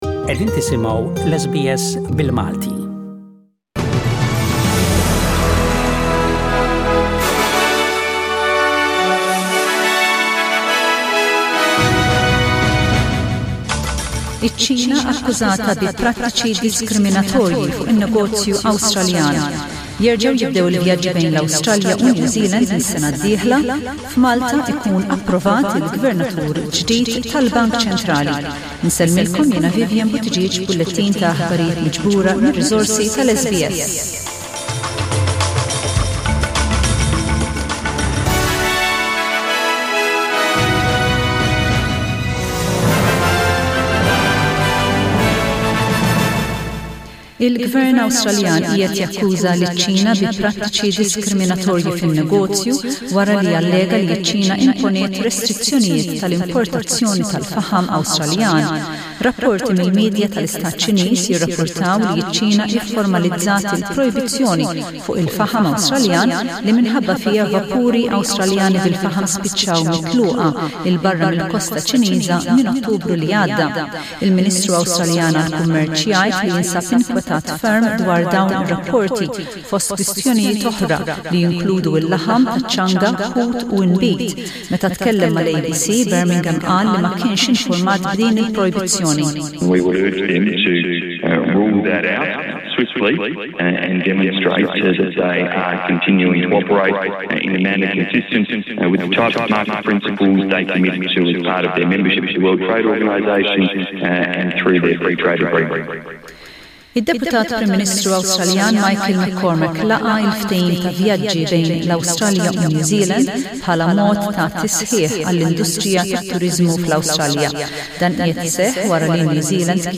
SBS Radio | News in Maltese: 15/12/20